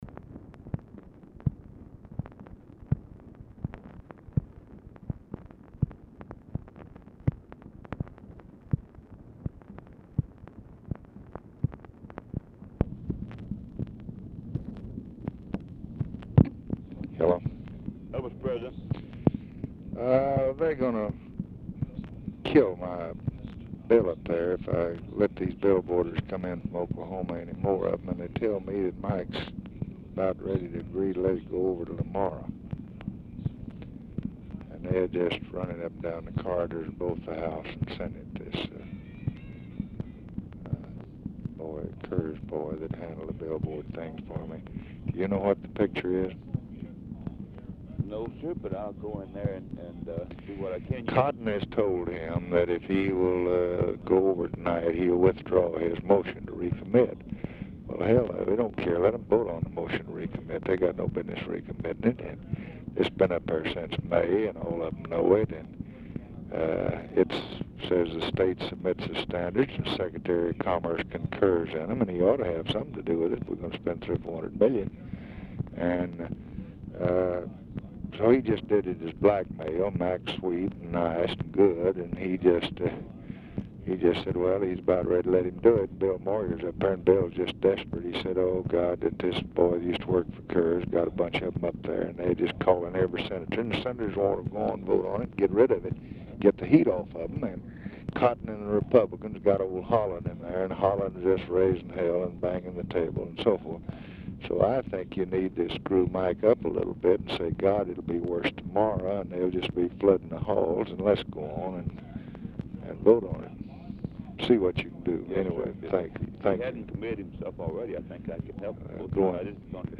Telephone conversation # 8873, sound recording, LBJ and RUSSELL LONG, 9/15/1965, 6:40PM
TV OR RADIO AUDIBLE IN BACKGROUND
Dictation belt